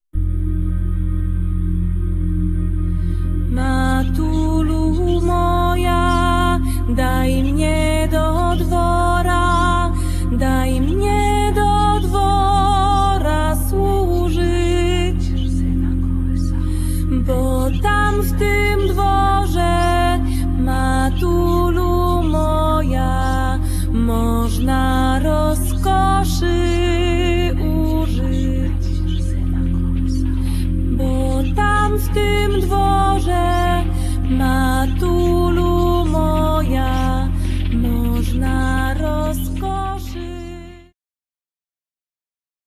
śpiew, skrzypce
kontrabas
akordeon
altówka
perkusja
flet poprzeczny, saksofon sopranowy